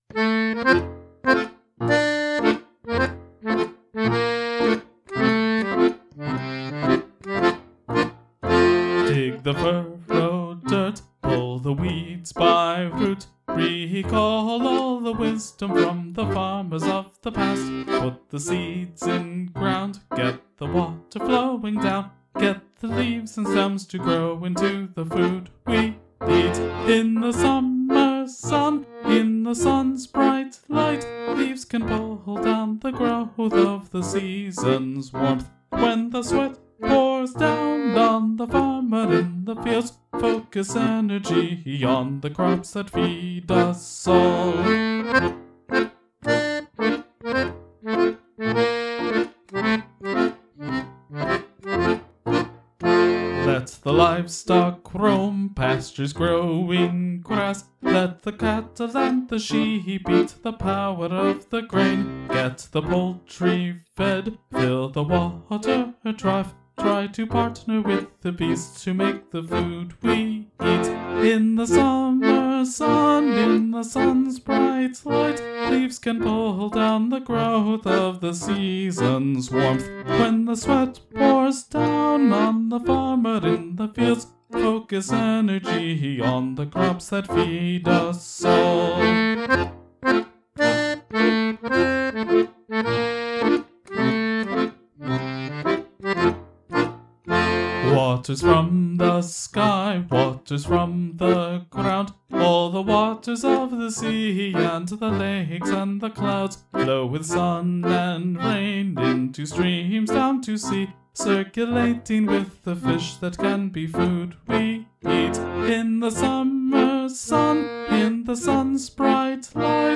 (For similar Norse-influenced reasons, the lyrical emphasis is more on alliteration than rhyme.) There are lots of variations on the dance, but the key concept is a pattern of stepping and slight hops in a straight polka rhythm.
summer_schottische.mp3